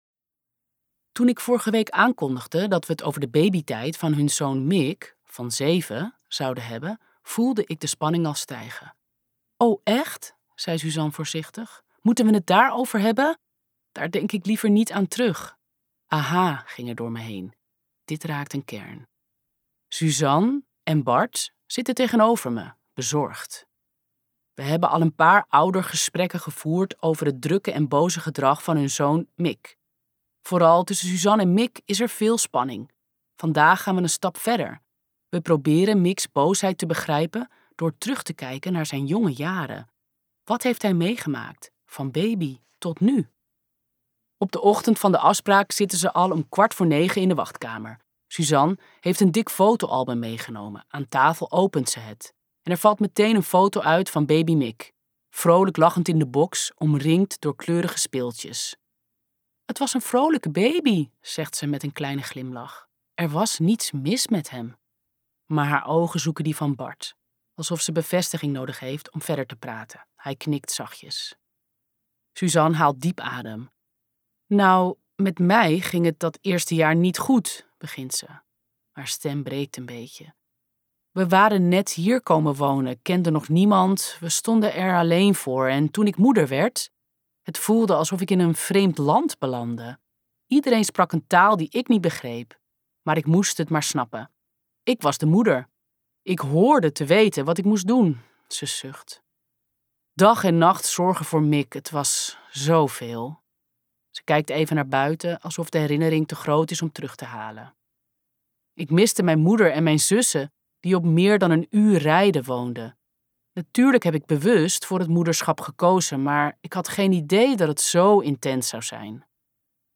Ambo|Anthos uitgevers - Opvoeden het hoeft niet zo perfect luisterboek